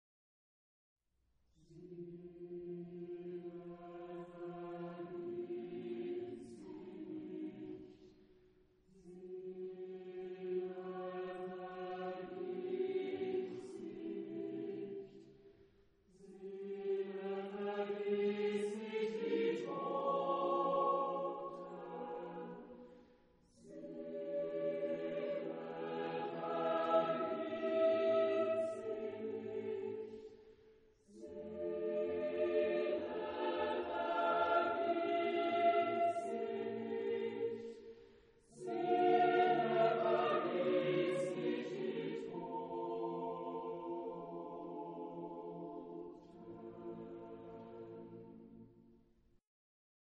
Genre-Stil-Form: Motette ; weltlich
Chorgattung: SSATBB  (6 gemischter Chor Stimmen )
Tonart(en): b-moll
Aufnahme Bestellnummer: Internationaler Kammerchor Wettbewerb Marktoberdorf